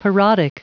Prononciation du mot parodic en anglais (fichier audio)
Prononciation du mot : parodic